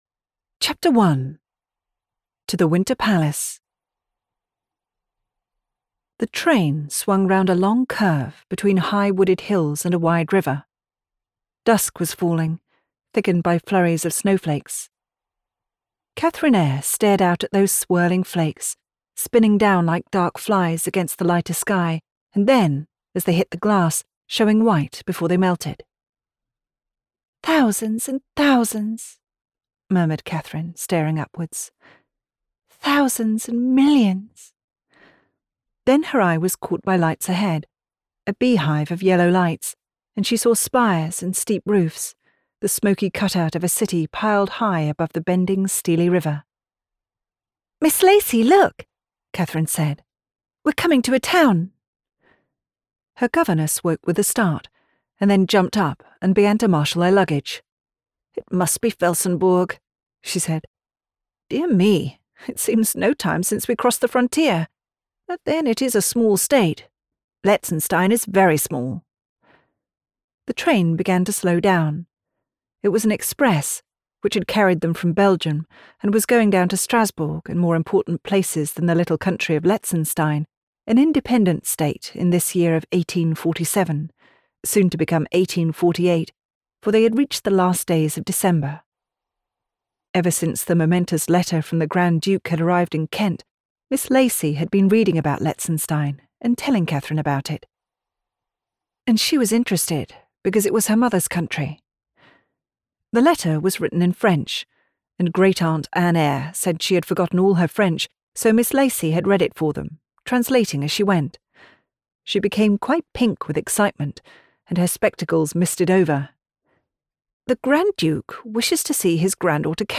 Female
English (British)
Yng Adult (18-29), Adult (30-50)
Childrens Audiobook
Words that describe my voice are Professional, Believable, Confident.
All our voice actors have professional broadcast quality recording studios.